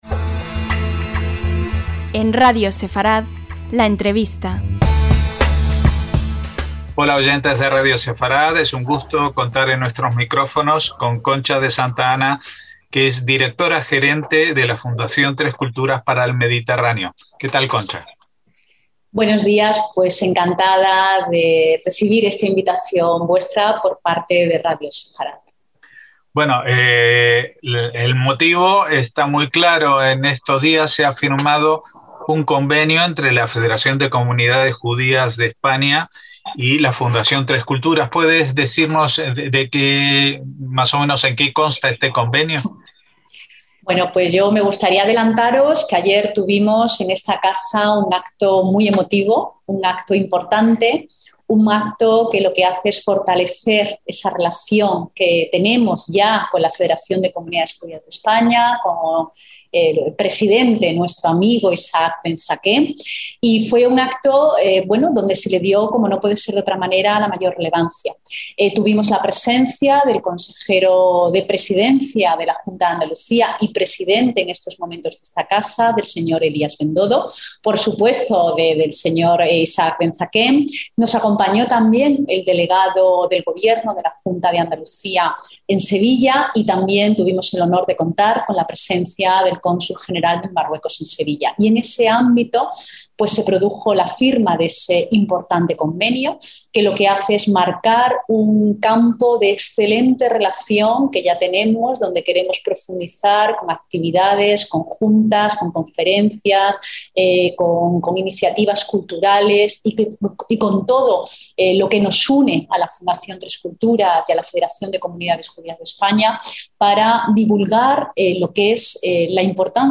LA ENTREVISTA - Esta semana la Fundación Tres Culturas de Sevilla ha firmado dos convenios con la Federación de Comunidades Judías de España.